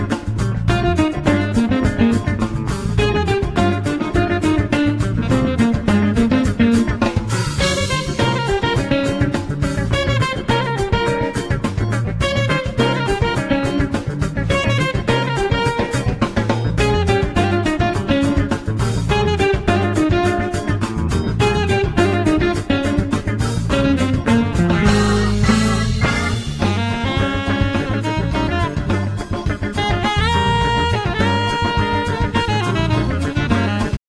Jazz-Funk guitar
jazz-funk-rhythm-blues music
B-3
tenor saxophone
alto sax
drums